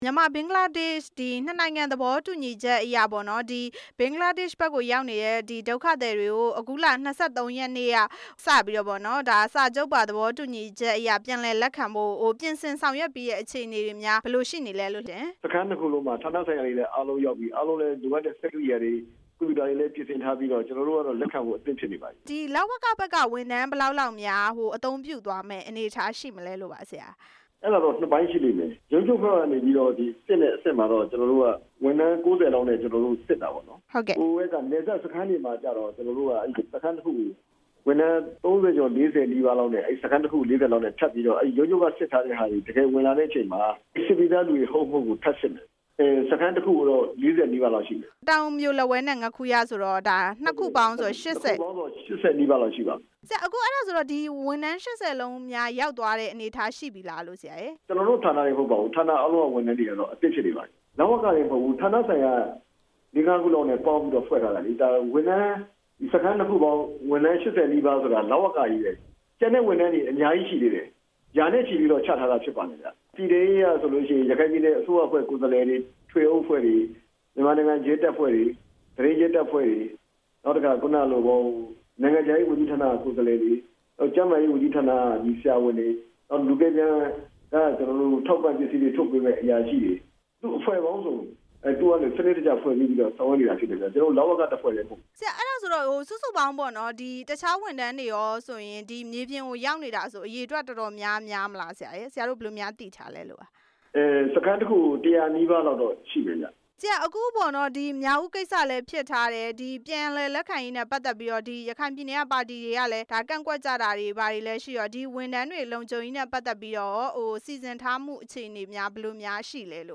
ဒုက္ခသည်တွေ လက်ခံရေး အဆင်သင့်ဖြစ်နေတဲ့အကြောင်း မေးမြန်းချက်
မြန်မာနိုင်ငံဘက် ပြန်လာမယ့် ဘင်္ဂလားဒေ့ရှ်ရောက် ဒုက္ခသည်တွေကို စိစစ်လက်ခံဖို့ ဌာနဆိုင်ရာ အသီးသီးက တာဝန်ရှိသူတွေ ပြန်လည်လက်ခံရေး စခန်းနှစ်ခုမှာ ရောက်ရှိနေပြီ ဖြစ်တာကြောင့် လက်ခံဖို့အဆင်သင့်ဖြစ်နေပြီလို့ အလုပ်သမား၊ လူဝင်မှုကြီးကြပ်ရေးနဲ့ ပြည်သူ့အင်အား ဝန်ကြီးဌာနဝန်ကြီး ဦးမြင့်ကြိုင် က ပြောပါတယ်။